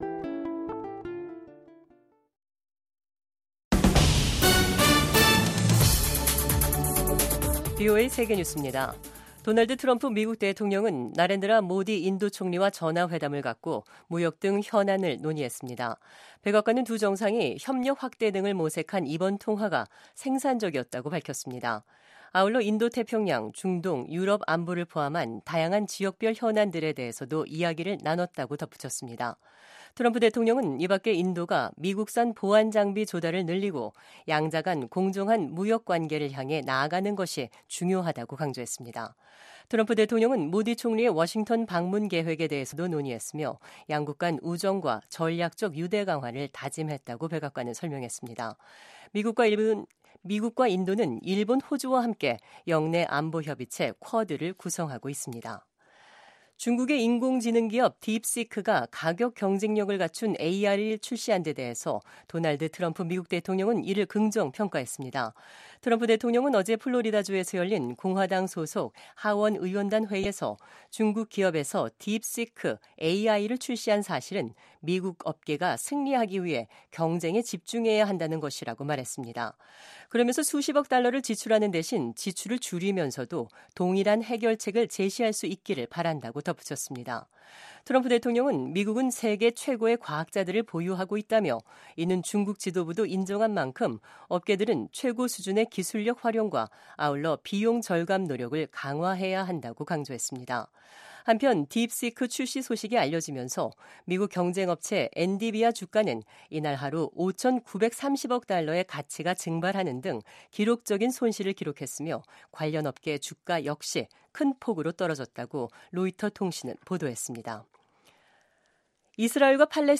VOA 한국어 간판 뉴스 프로그램 '뉴스 투데이', 2025년 1월 28일 3부 방송입니다. 한국과 쿠바 두 나라는 수교 11개월만에 대사관 개설과 대사 부임 절차를 마무리했습니다. 트럼프 대통령이 북한을 ‘핵 보유국’이라고 지칭한 이후 한국 내에서 커지고 있는 자체 핵무장론과 관련해 미국 전문가들은 미한동맹과 역내 안정에 부정적인 영향을 미칠 것이라고 전망했습니다.